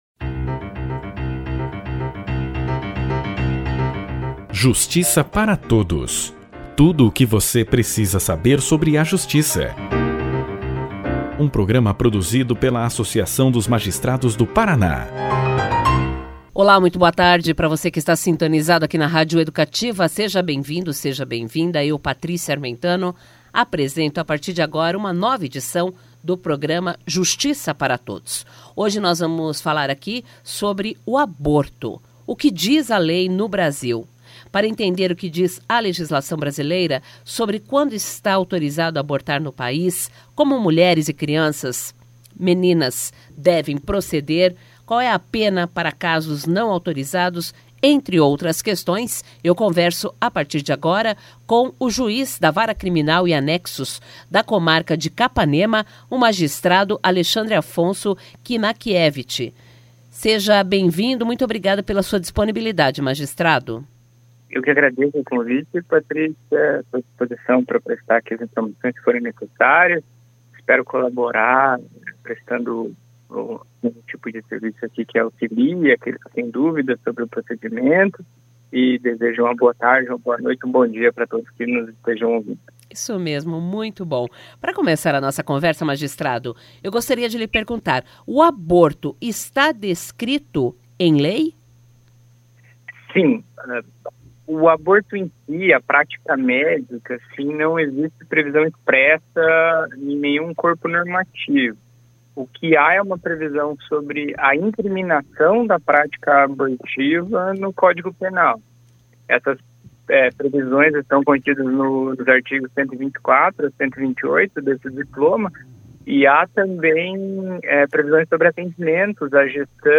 Entrevista - Juiz Alexandre Afonso Knakiewicz
O programa de rádio da Amapar, Justiça Para Todos, entrevistou o juiz Titular da Vara Criminal e Anexos da comarca de Capanema, Alexandre Afonso Knakiewicz, para explicar os casos em que o aborto é um direito garantido no Brasil.